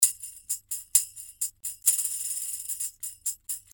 65 BPM Tambourine (4 variations)
4 variations of real tambourine loops.